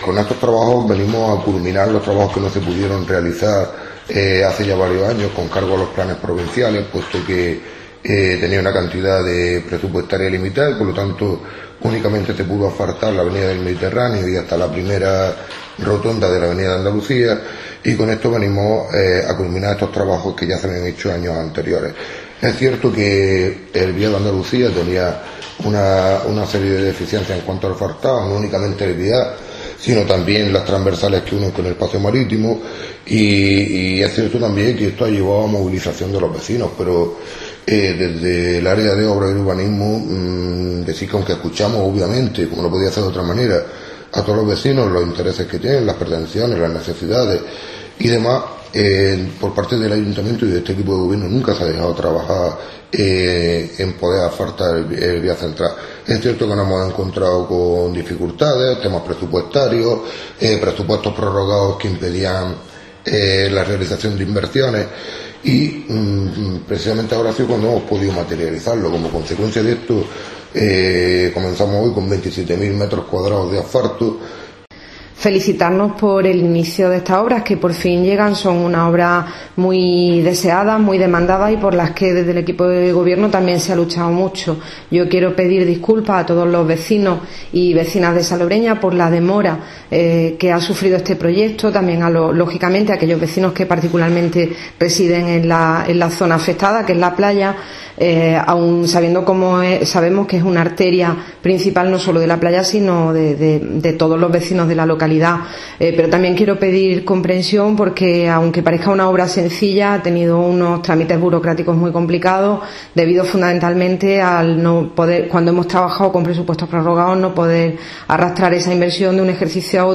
Dan comienzo las obras del Vial de la Playa de Salobreña. Javier Ortega, concejal de urbanismo y María Eugenia Rufino, alcaldesa.